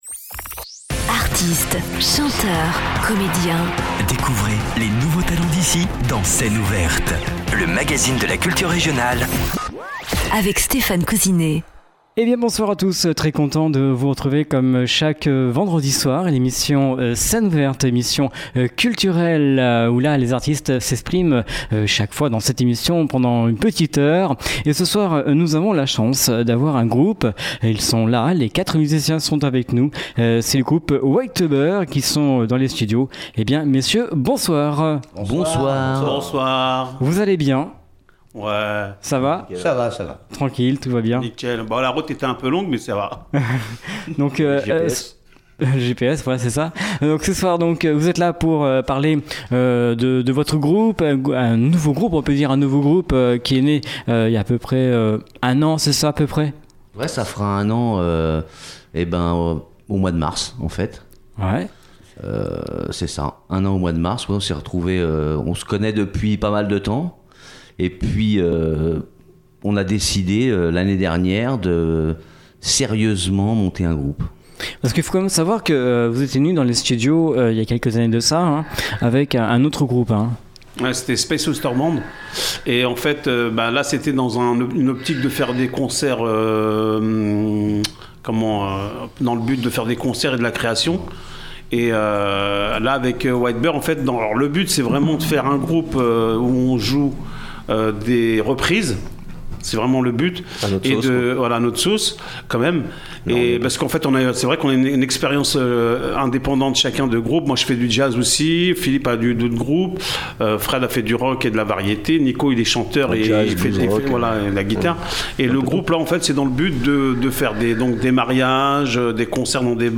groupe de reprise funk disco rock